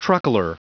Prononciation du mot truckler en anglais (fichier audio)
Prononciation du mot : truckler